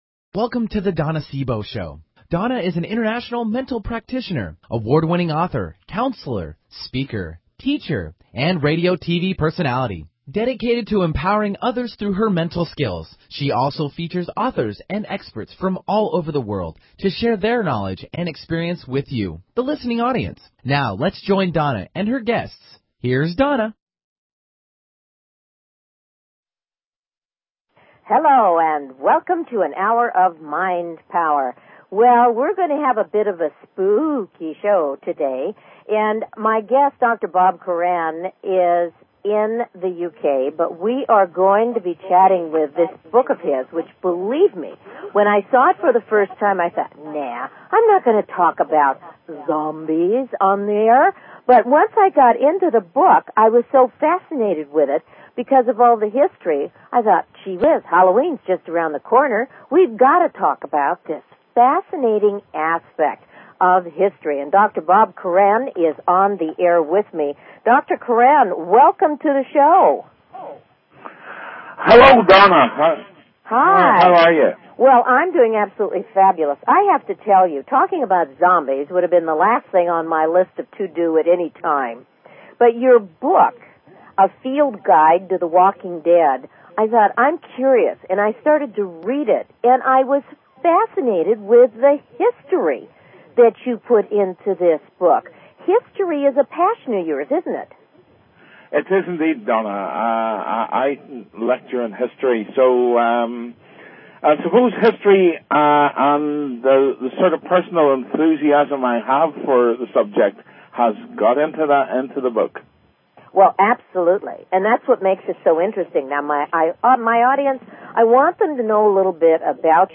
Talk Show Episode, Audio Podcast
Her interviews embody a golden voice that shines with passion, purpose, sincerity and humor.